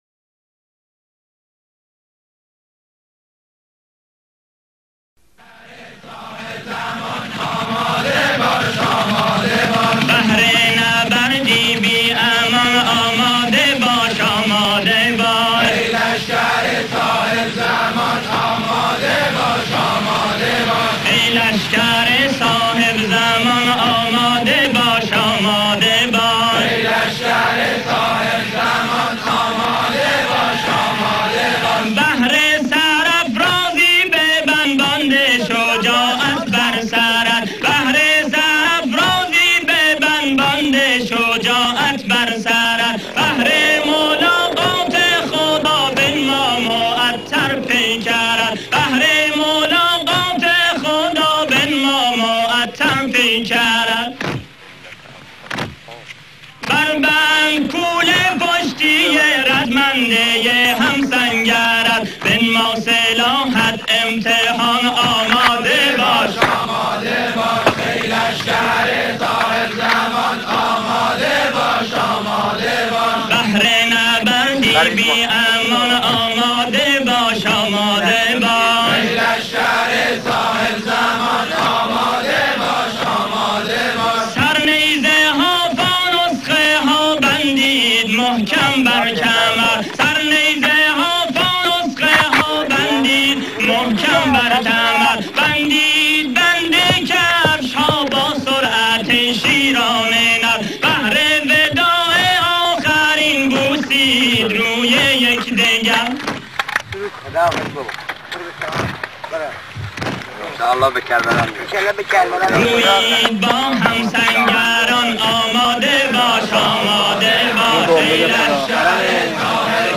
Download Old Remix BY